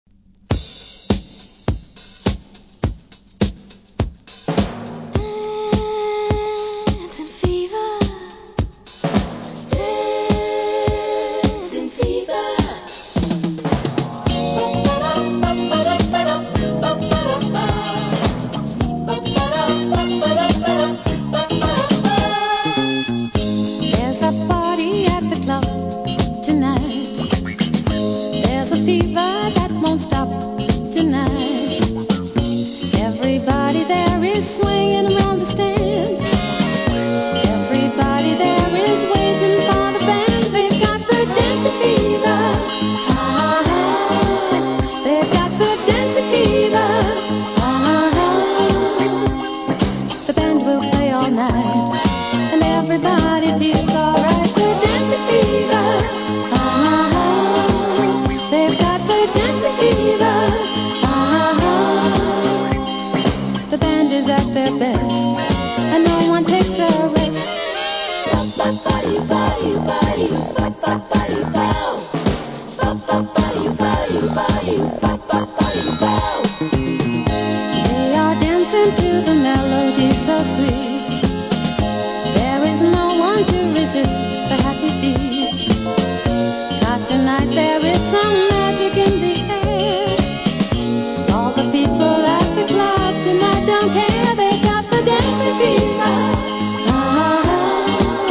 DISCO
「今夜のダンスは朝まで止まらない」とのダンス賛歌♪